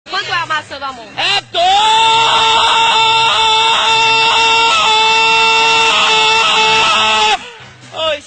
Play, download and share eh dois original sound button!!!!
palhaao-irritado-a-dois-mp3cut.mp3